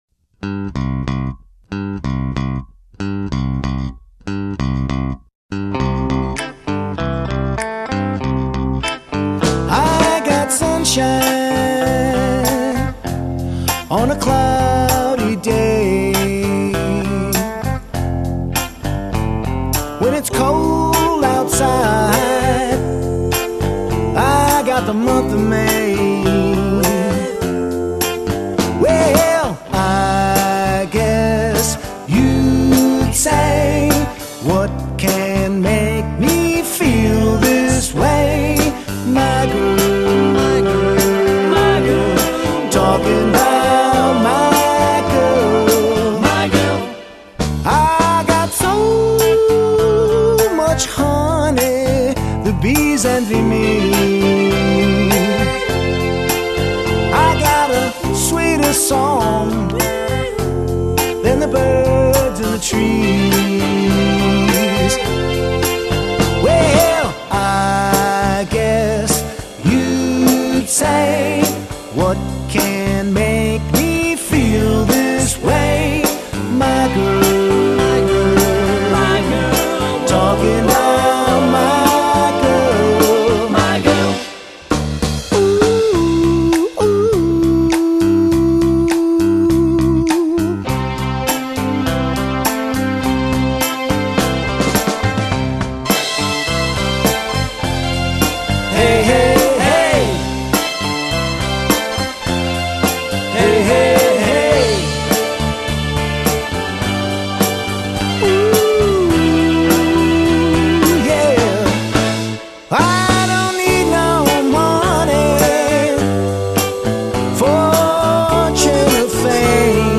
studio album, I sing & play